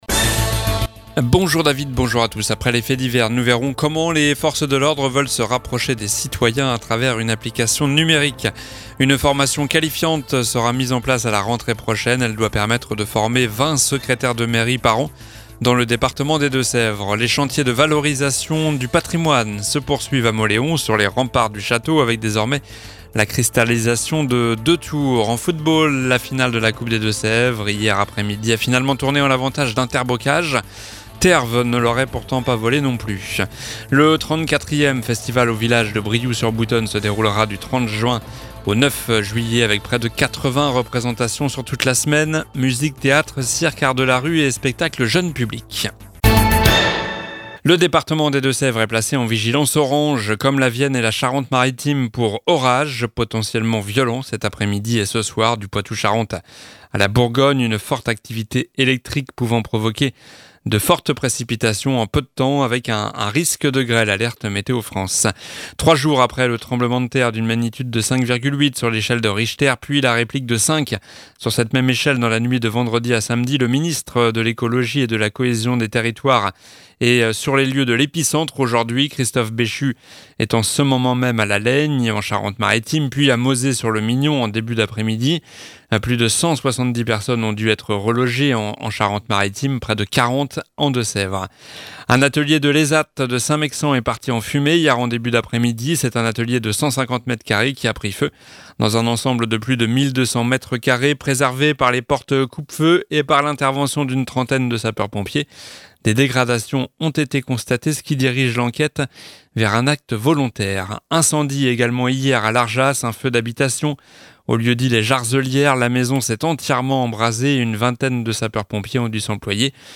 Journal du lundi 19 juin (midi)